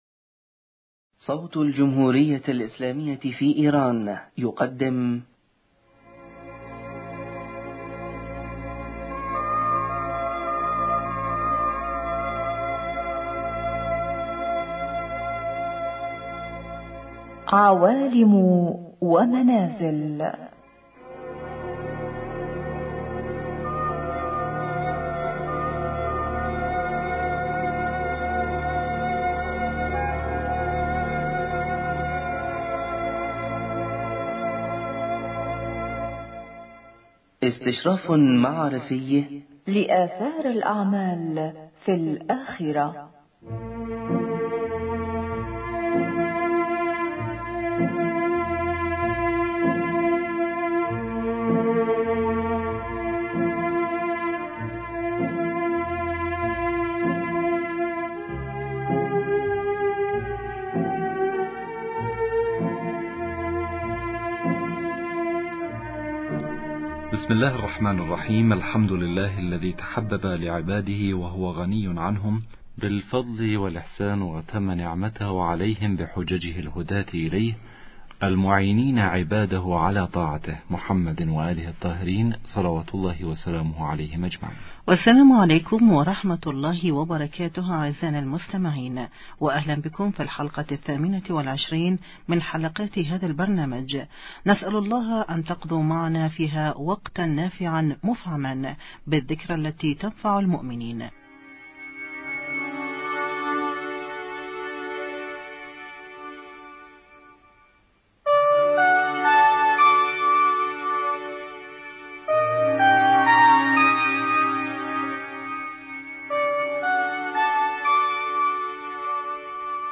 يجيب عن أسئلة المستمعين